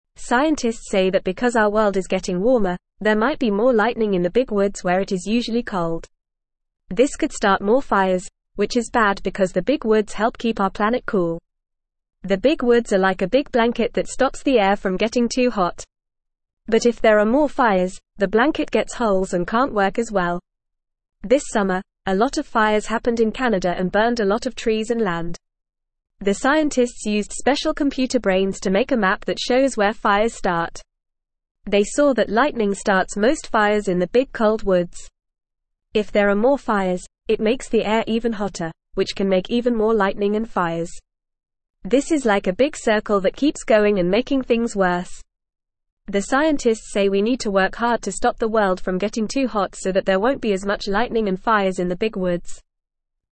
Fast
English-Newsroom-Beginner-FAST-Reading-More-Lightning-and-Fires-in-Cold-Woods.mp3